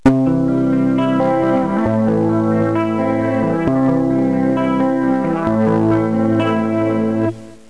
Le guitariste électrique - Les arpèges
Les arpèges
Jouer en arpège consiste à jouer les notes d'accords séparément.
arpeges.wav